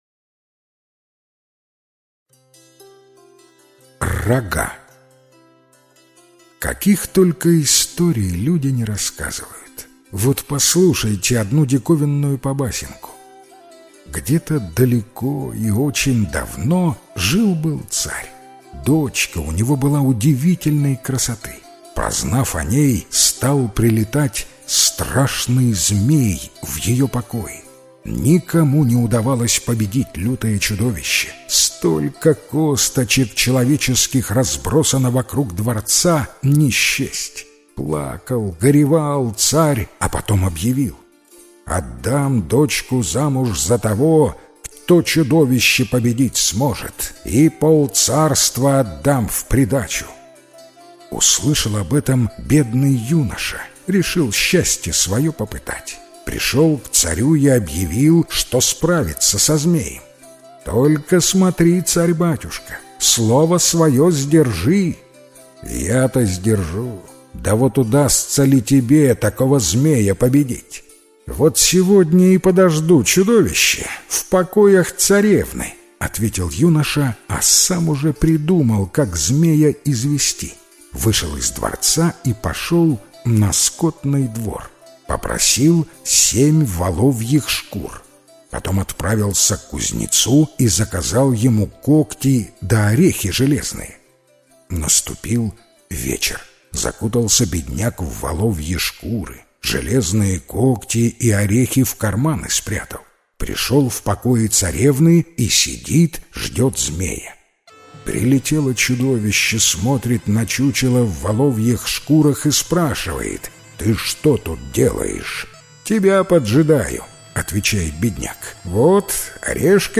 Рога - белорусская аудиосказка - слушать онлайн